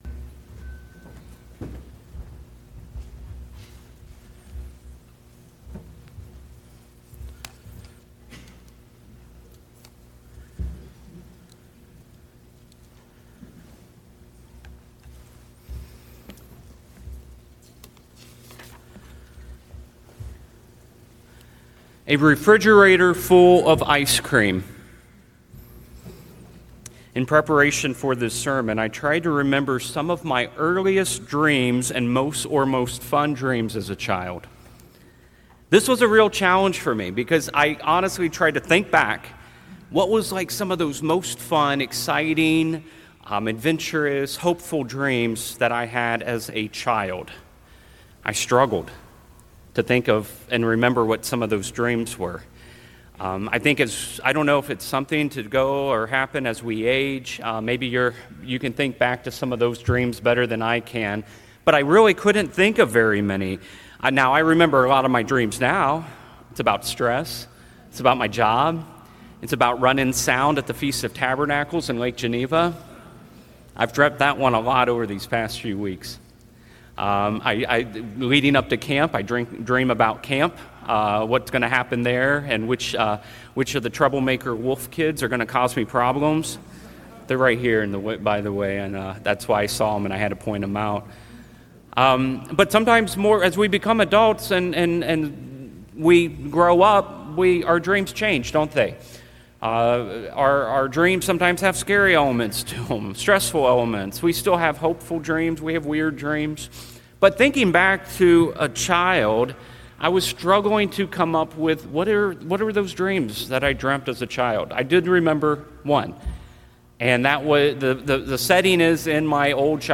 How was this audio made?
Children’s thoughts or dreams may even include what they want to be when they grow up. We are here at the Feast of Tabernacles to live and experience part of the dream or vision that God wants us to have of a better time to come in the future.